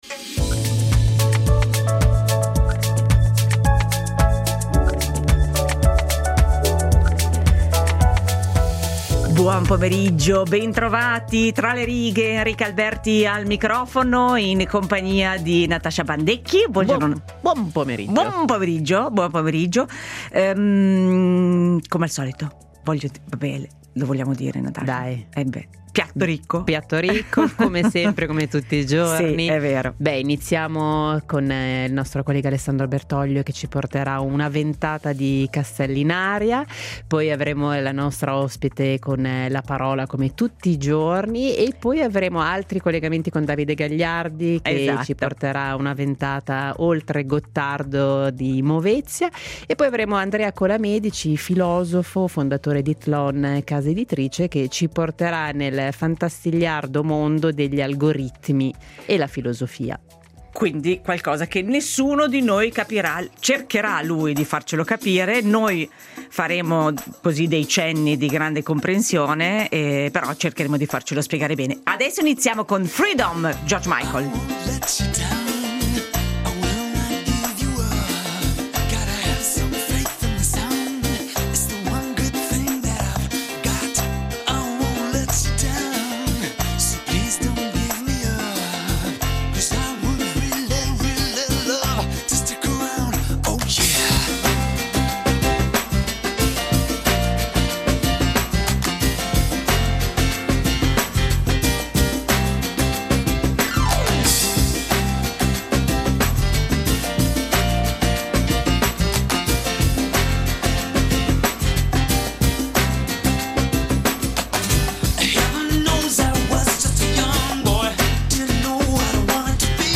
Castellinaria: intervista all’attore Umberto Orsini